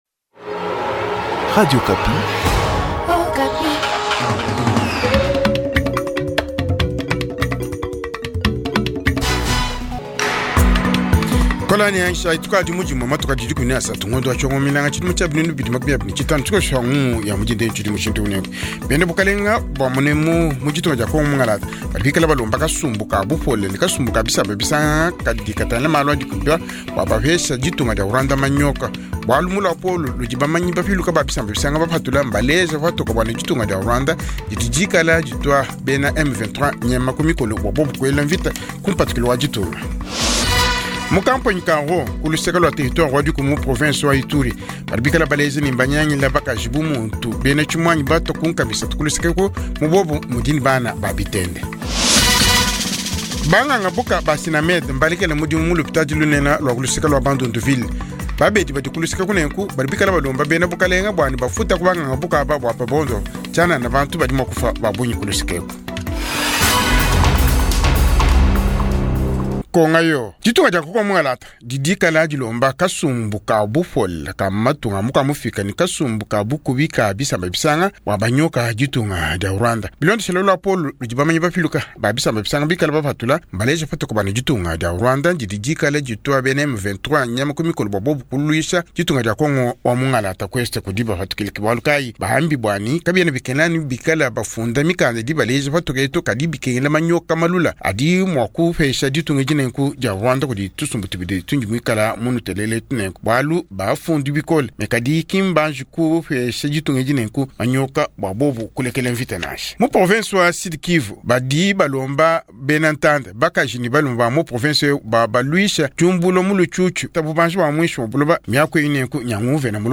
Journal Tshiluba